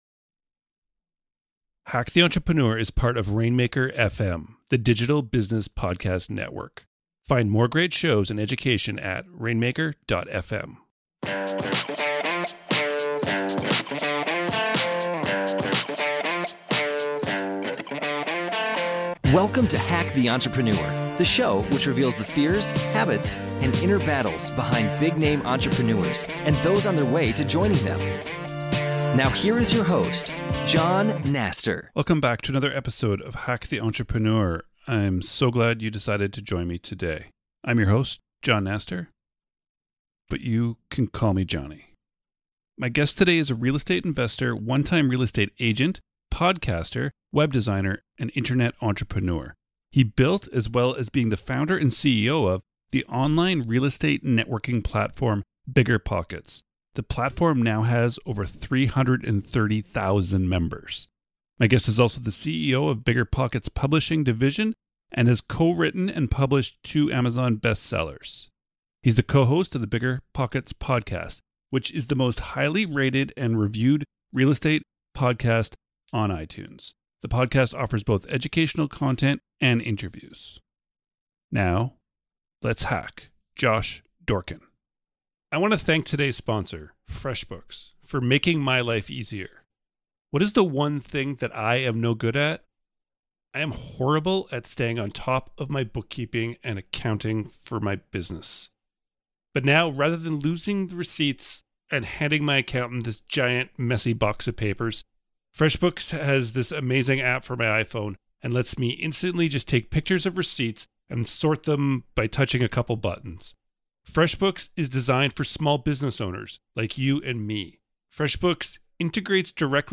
My guest today is a Real Estate investor, one-time Real Estate agent, podcaster, web designer and internet entrepreneur.